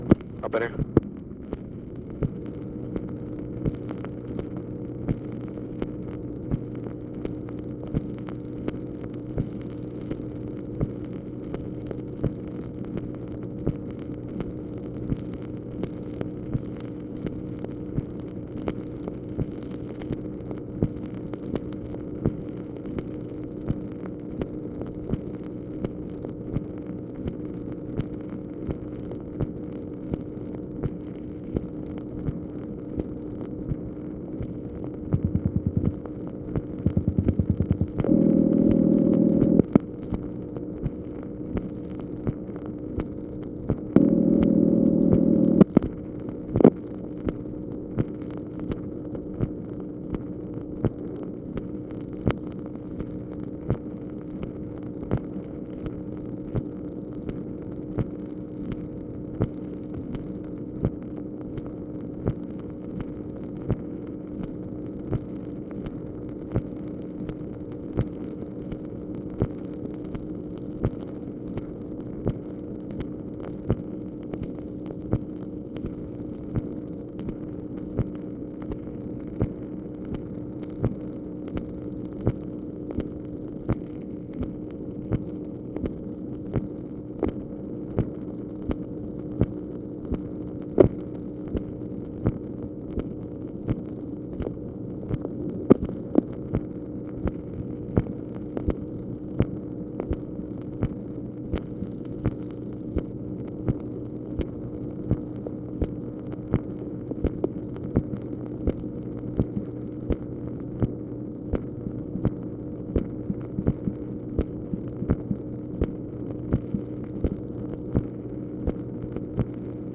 MACHINE NOISE, March 9, 1965
Secret White House Tapes | Lyndon B. Johnson Presidency